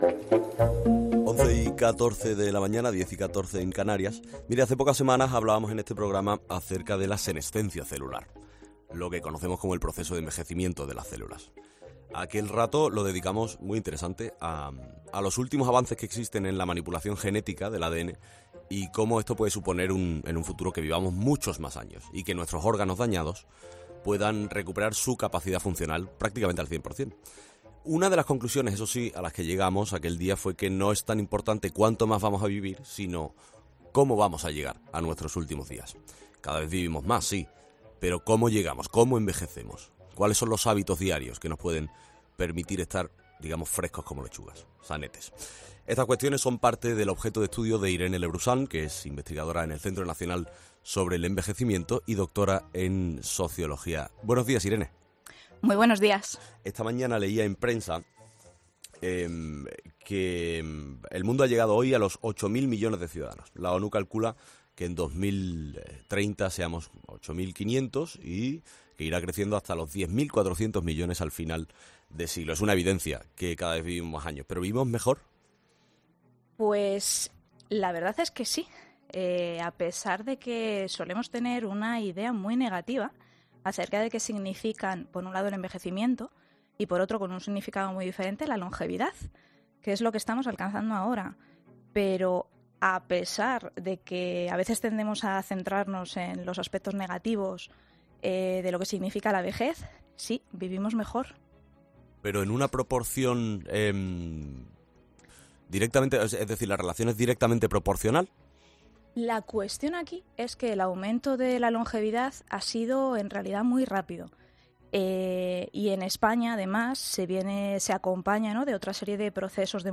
A pesar de que a veces nos centramos en los aspectos negativos de los que significa la vejez, vivimos mejor", comienza diciendo la experta.